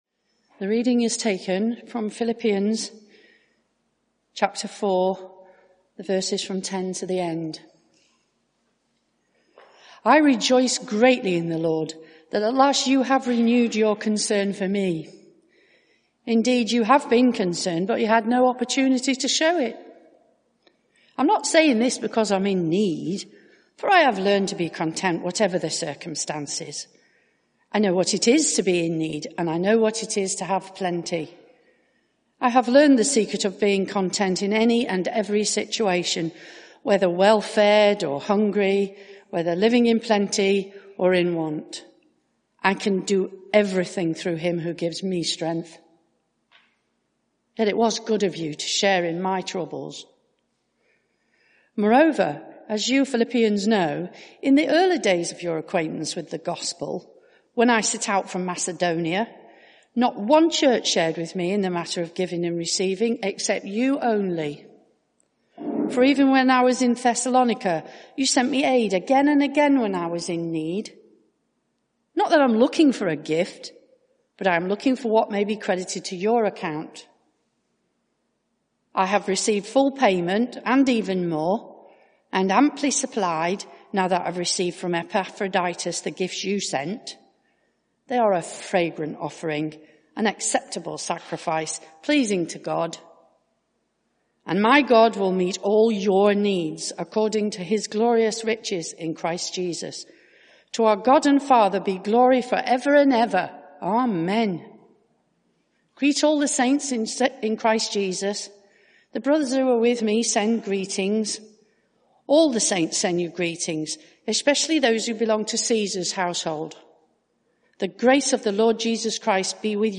Media for 11am Service on Sun 13th Jun 2021 11:00 Speaker
Sermon (audio)